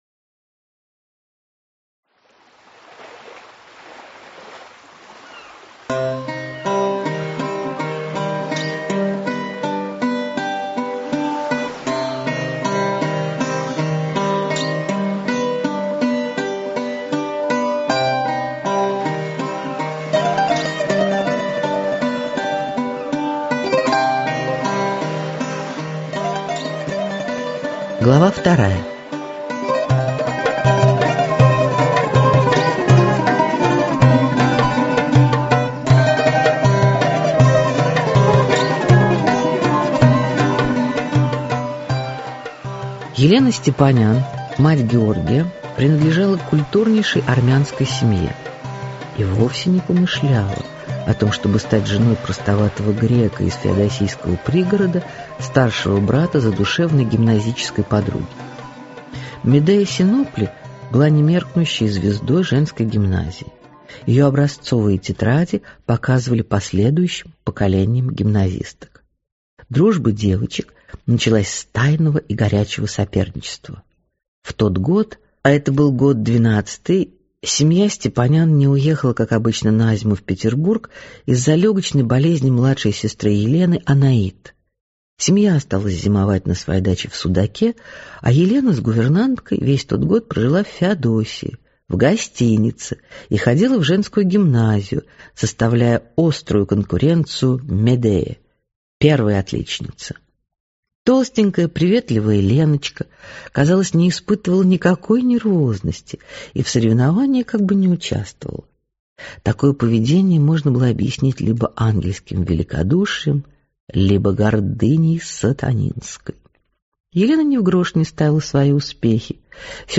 Аудиокнига Медея и ее дети - купить, скачать и слушать онлайн | КнигоПоиск